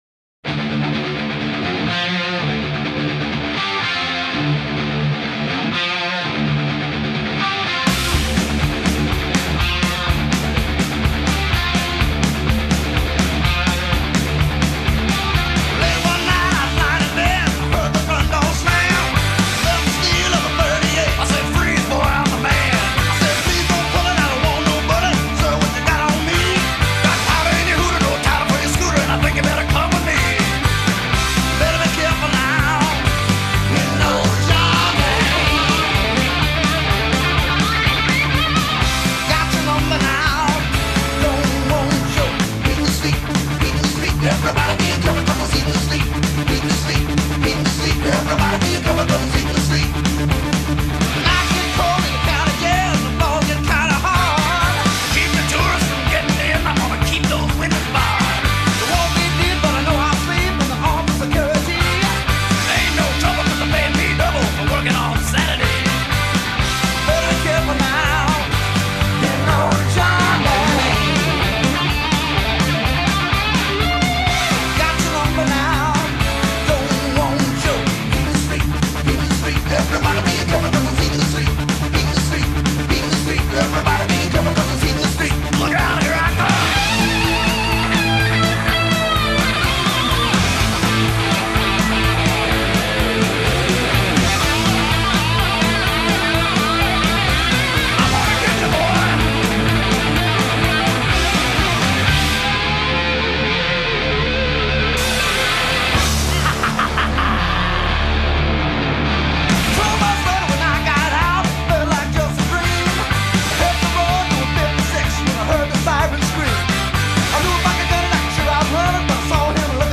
скорее хард.